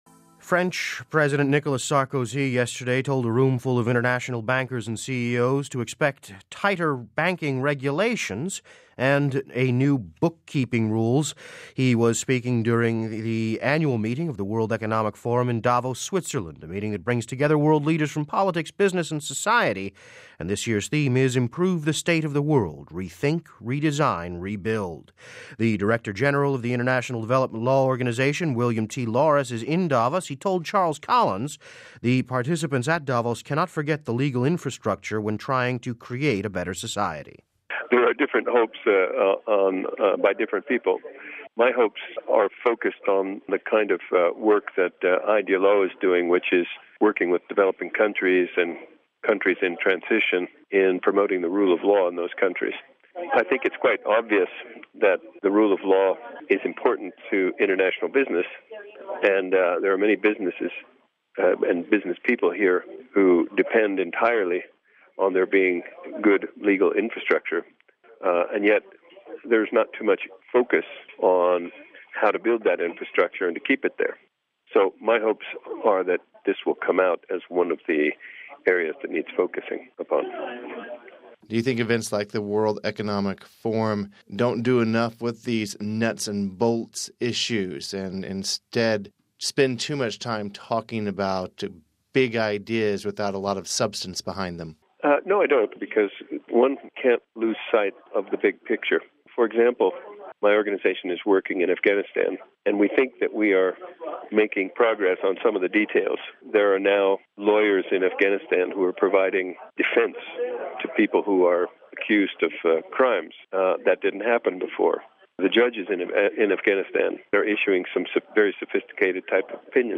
He was speaking during the annual meeting of the World Economic Forum in Davos, Switzerland, a meeting that brings together world leaders from politics, business, and society.